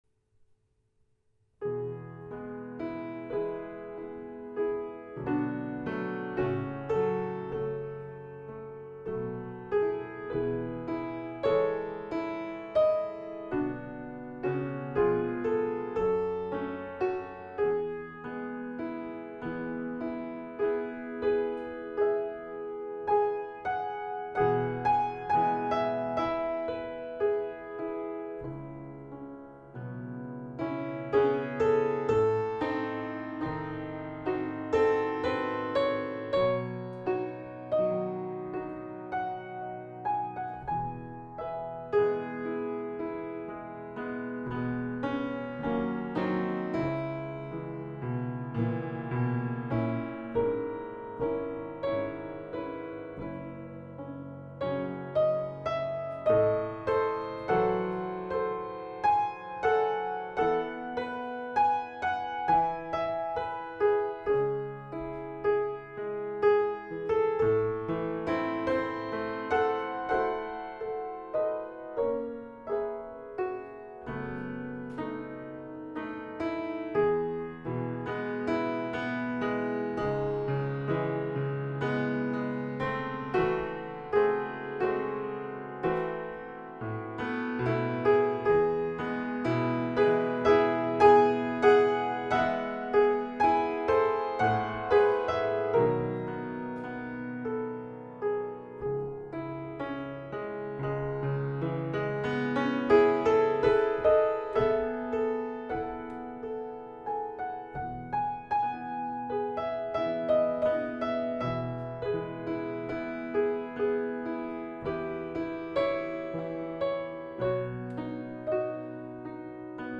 Piano’s out of tune, but then – after all these years so am I…